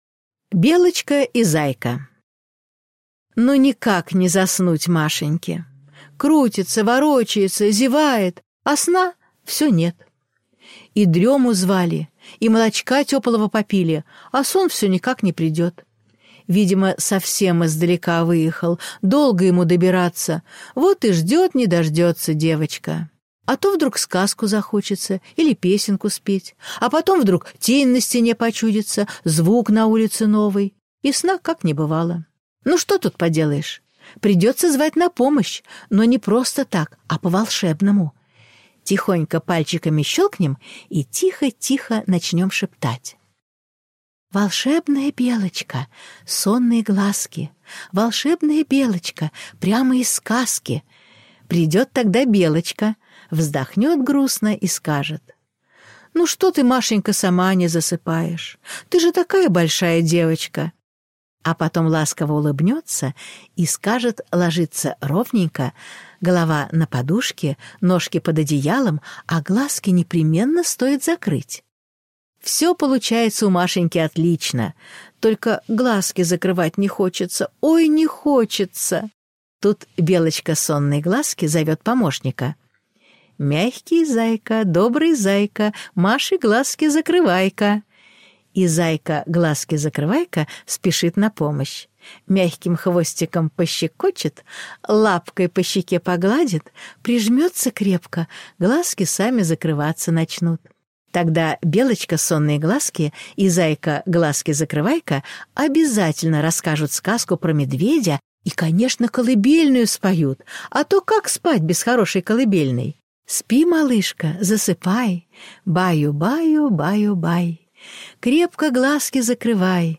Белочка и зайка - аудиосказка Анны Деус - слушать онлайн